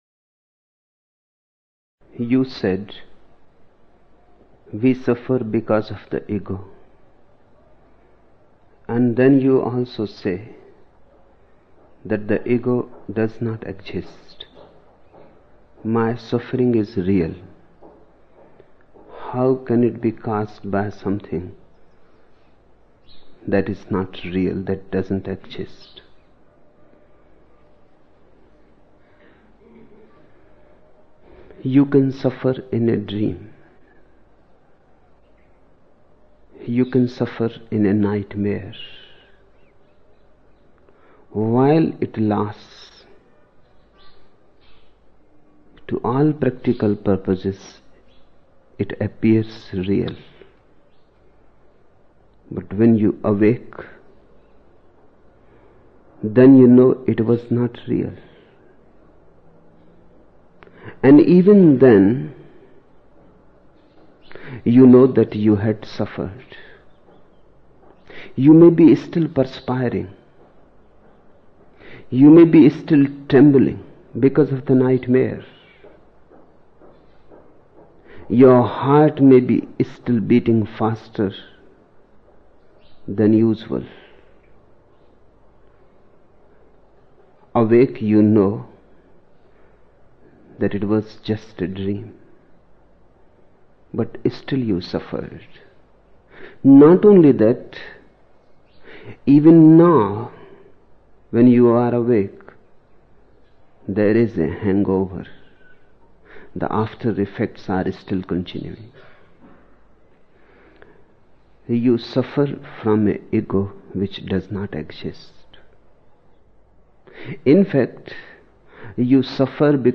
28 October 1975 morning in Buddha Hall, Poona, India